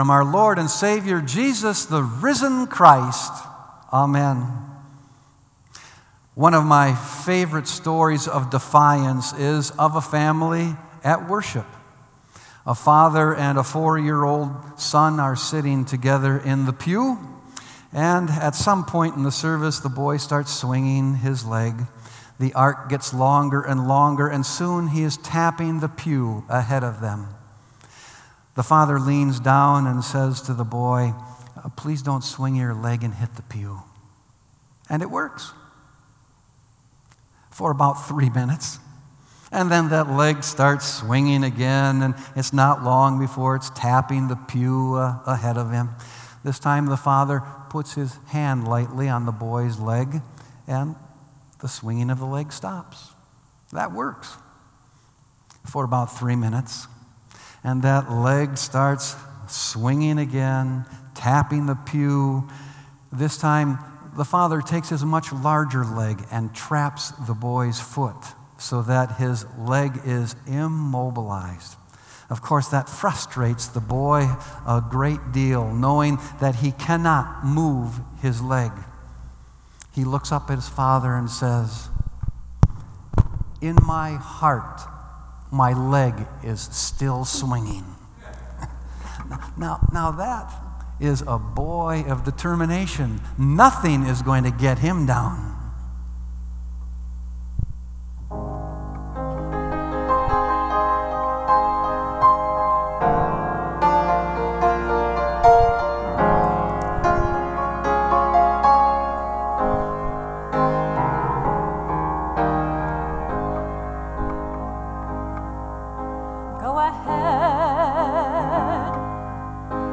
Sermon “I Will Rise Again”